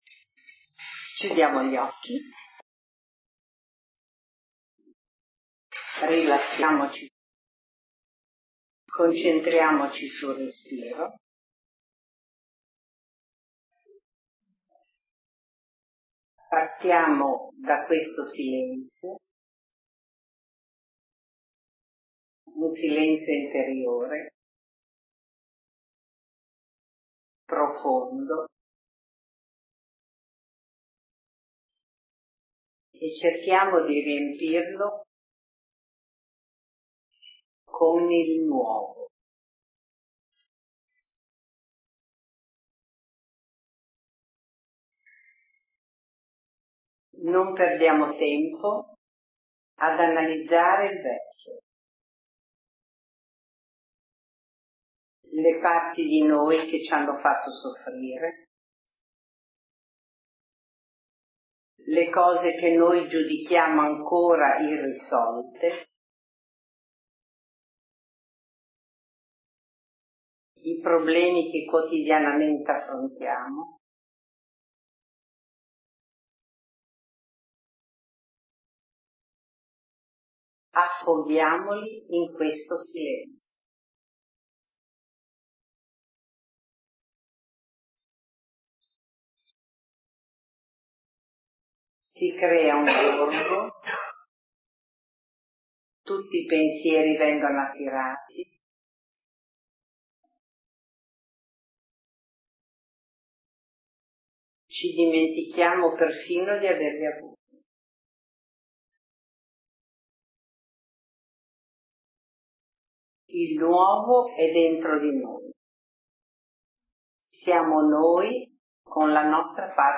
Immergersi nel Nuovo – meditazione
immergersi-nel-nuovo_meditazione.mp3